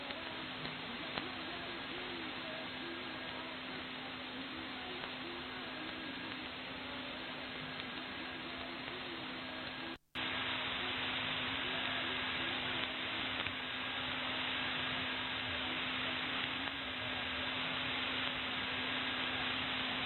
Both devices were operated with the same settings and were supplied with the antenna signal via the antenna distributor of Elad ASA-62.
Second 10 - 20> Winradio G33DDC Excalibur Pro
AMS-8KHz
The audio comparisons of long, medium and shortwave were made with the Kreuzloop RLA4E / 2.